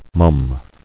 [mum]